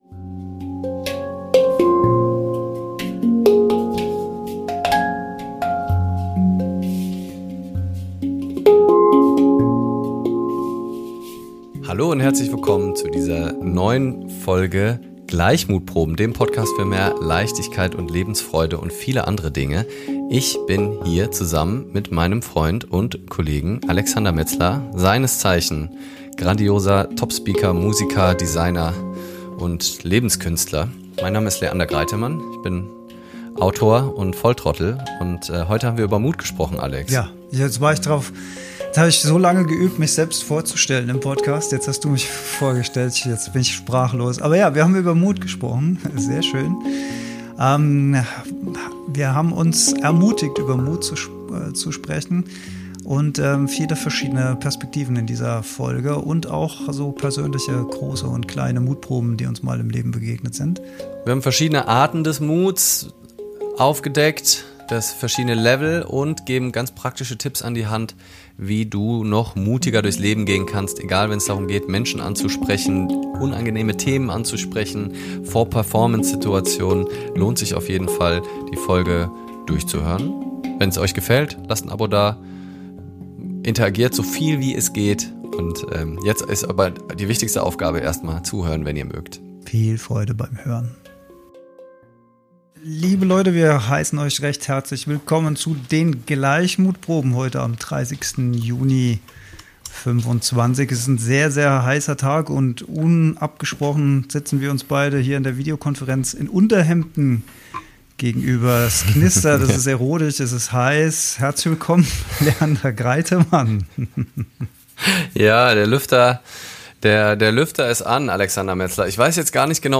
Tipp: Es gibt knackige Alltagshacks und eine extra Portion Musik am Ende!